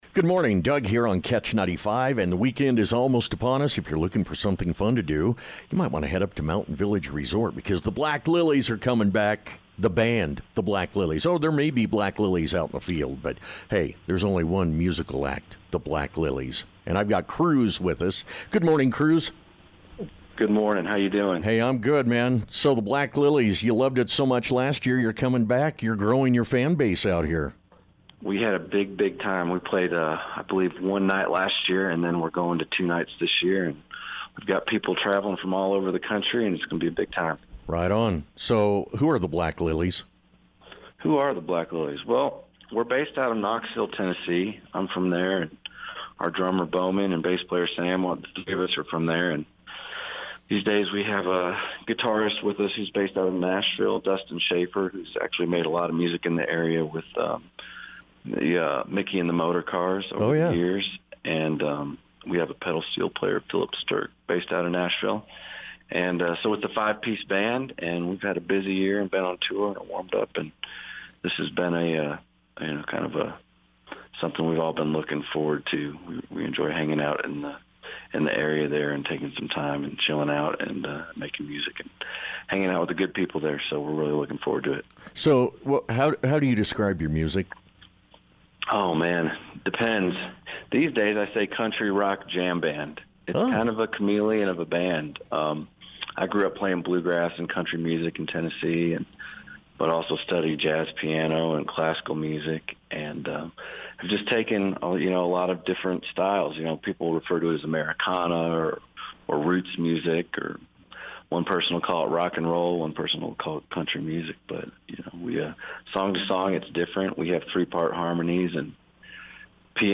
The Black Lillies Interview w/KECH